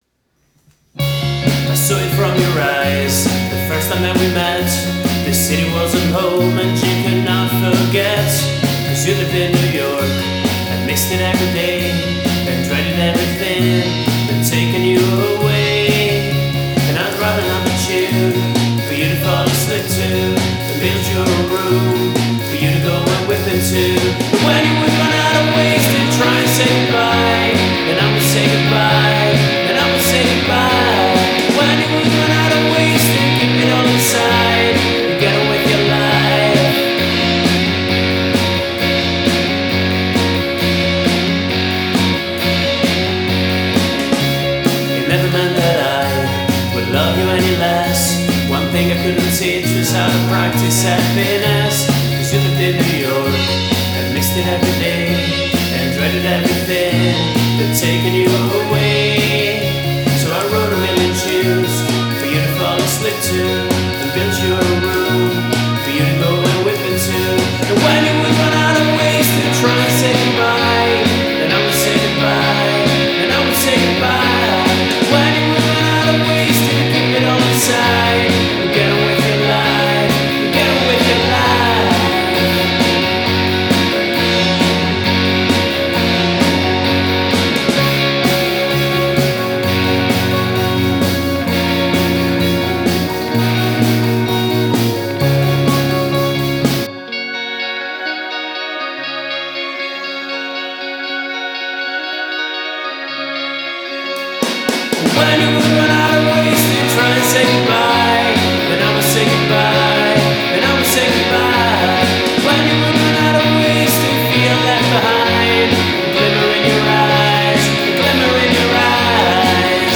vocals, guitars, bass, keyboards, drums